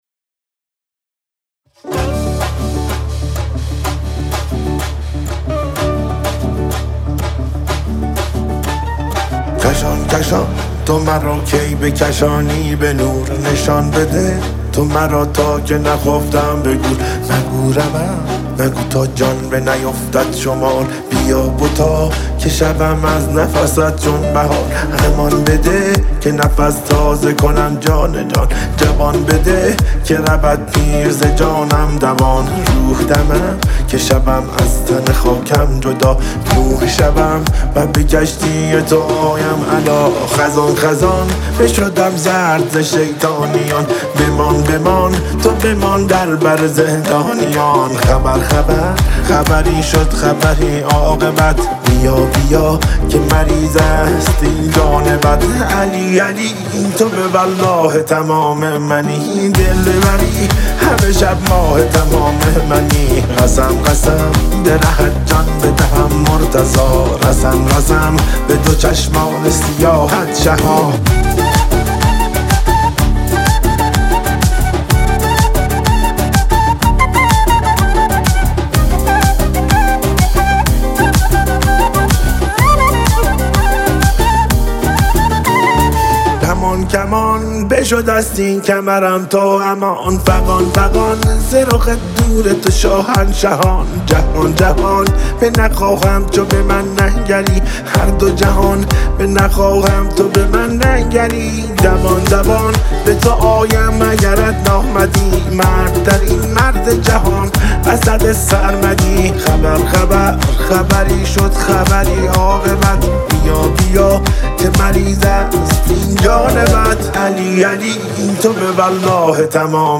با صدای گرم
ملودی دلنشین